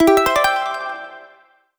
Sound effect of 1-Up Bonus Game HUD in Mario vs. Donkey Kong (Nintendo Switch)